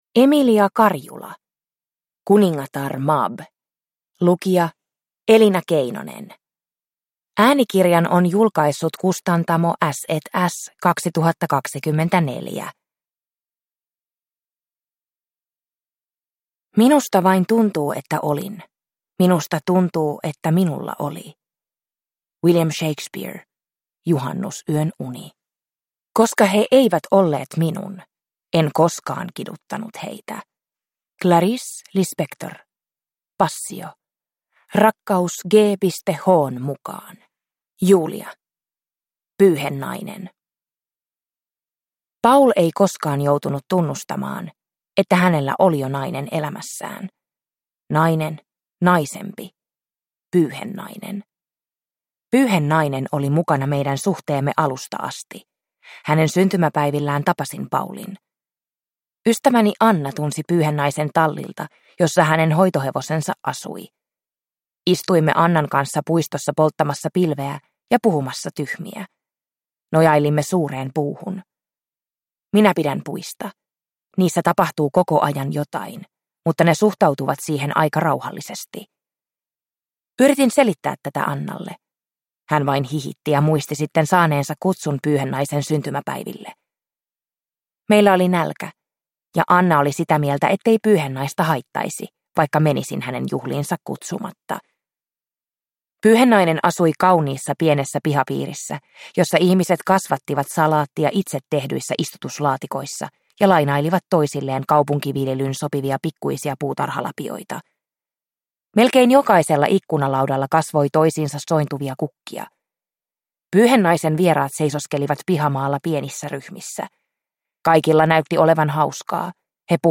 Kuningatar Mab (ljudbok) av Emilia Karjula